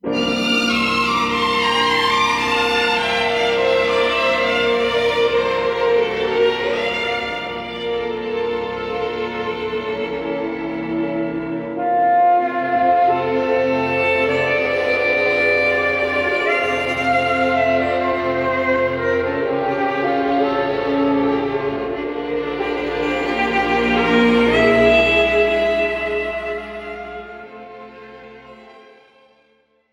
This is an instrumental backing track cover
• Key – A♭
• Without Backing Vocals
• No Fade